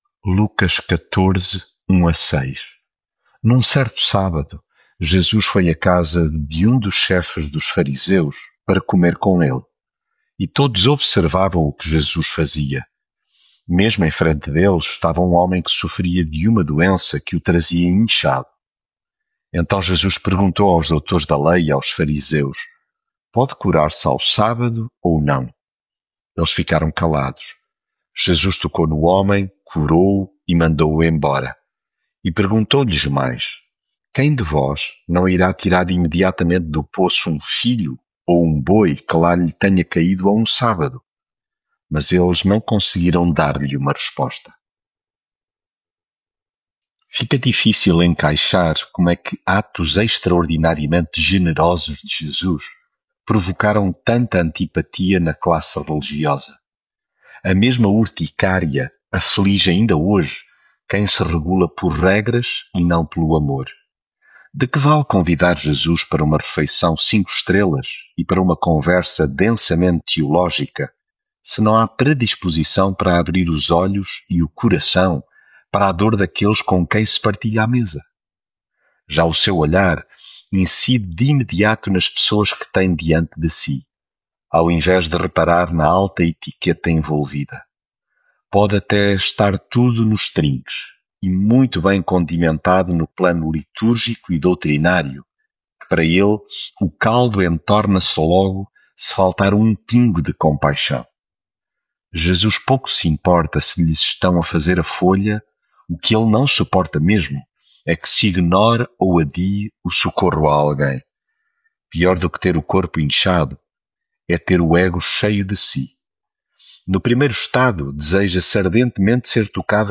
Devocional
leitura bíblica